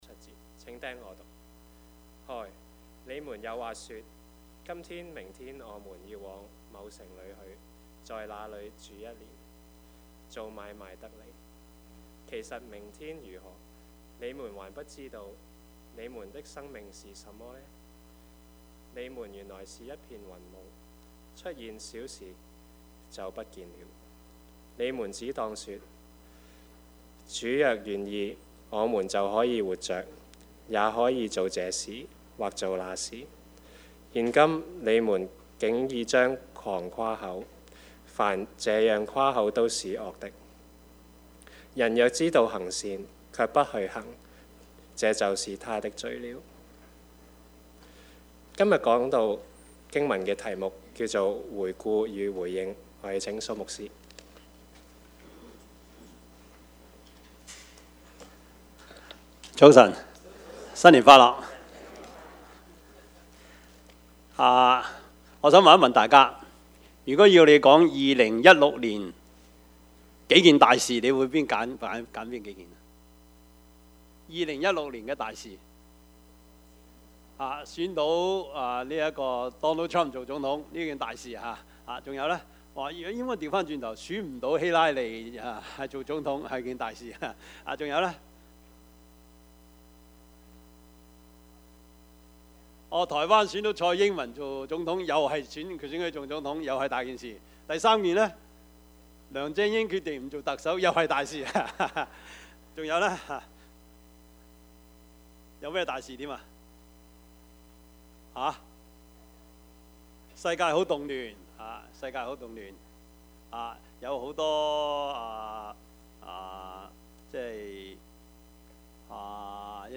雅各書 四：13-17 Service Type: 主日崇拜 Bible Text
Topics: 主日證道 起初 »